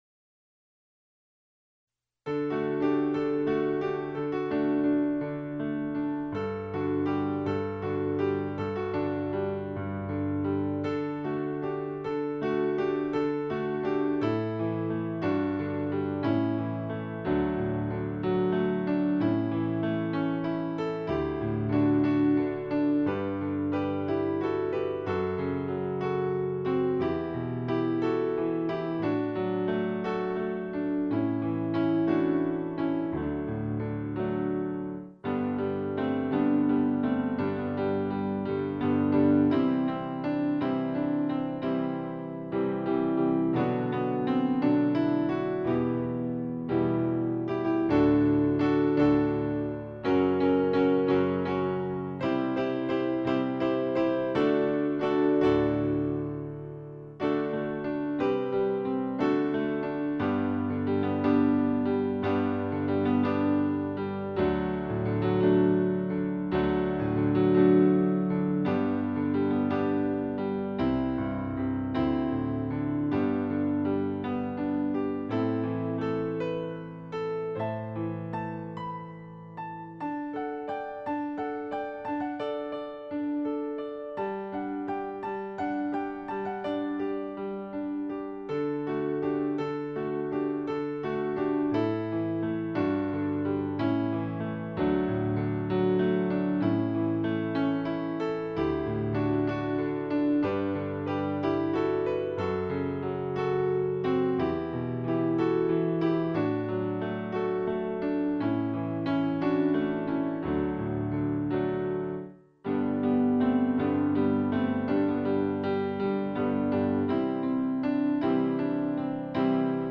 Be Thou an Example: accompaniment only
Be Thou An Example accompaniment.mp3